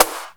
INSNAREFX7-R.wav